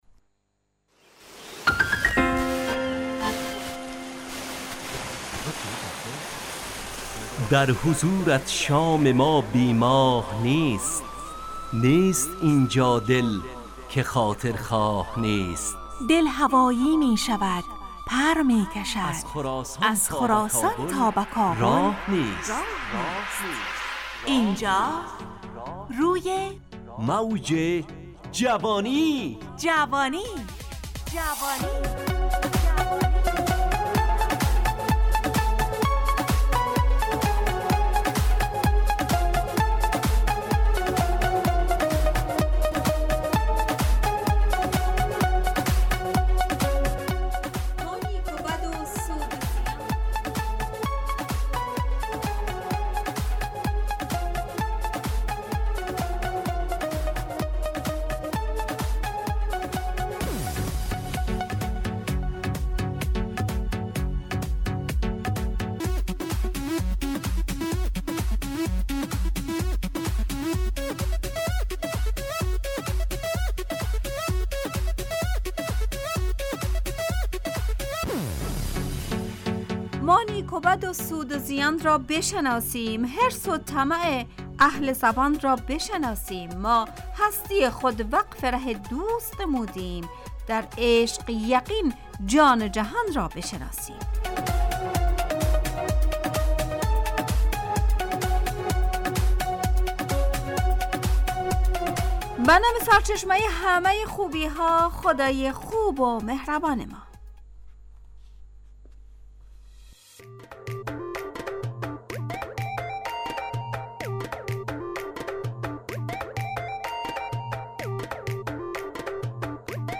همراه با ترانه و موسیقی مدت برنامه 70 دقیقه .
روی موج جوانی برنامه ای عصرانه و شاد